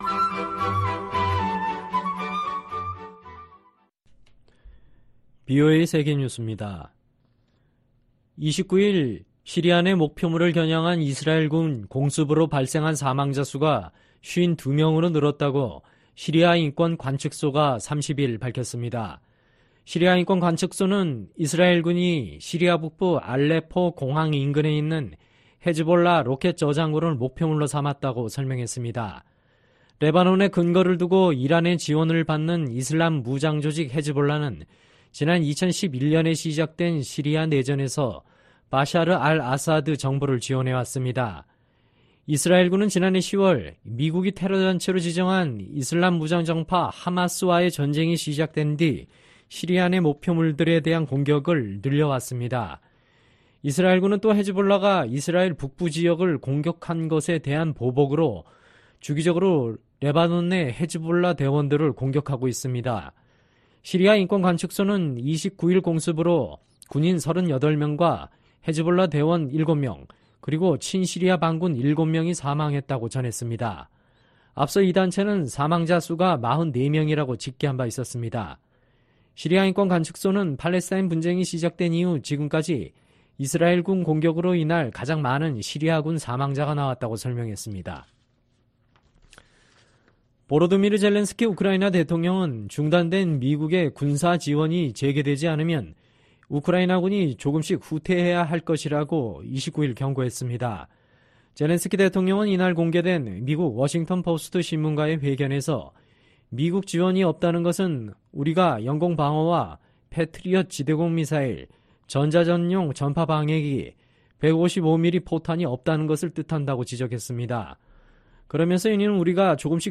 VOA 한국어 방송의 토요일 오후 프로그램 3부입니다. 한반도 시간 오후 10:00 부터 11:00 방송됩니다.